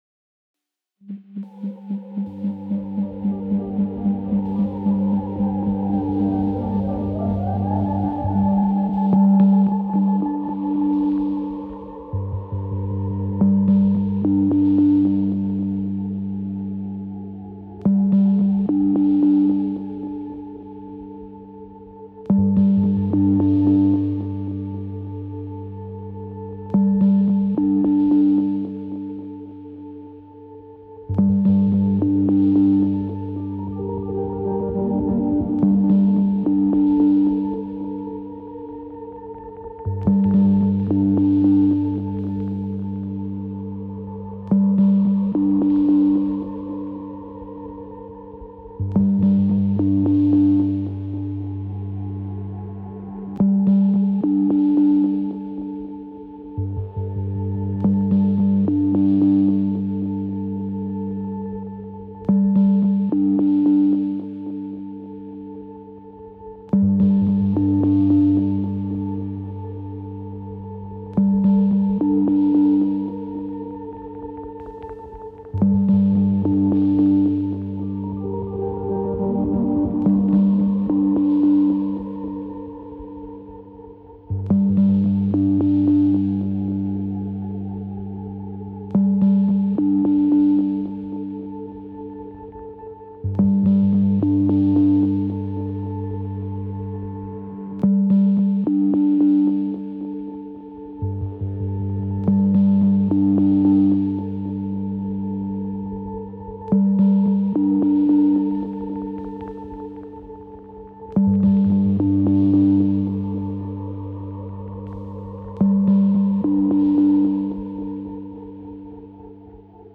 Accurate audio detail, wound around 8mm sound.